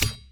sounds: added battle sounds
sword3.wav